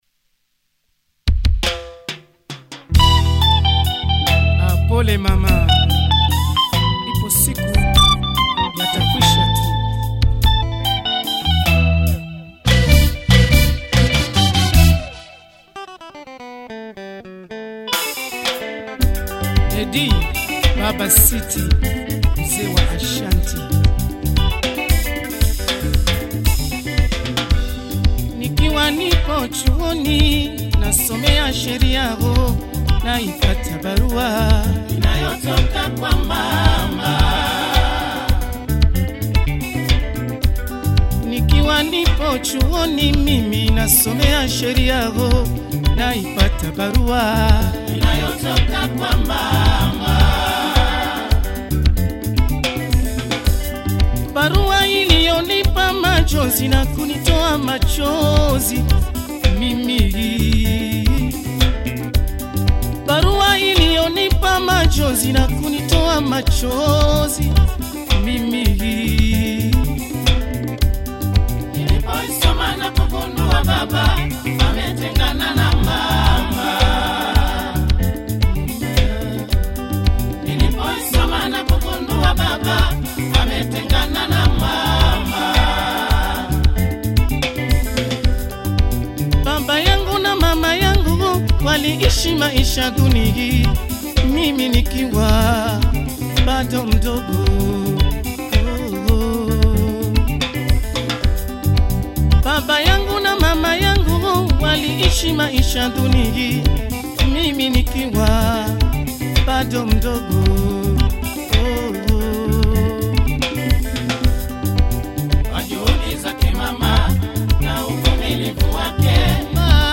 Zilipendwa / Rhumba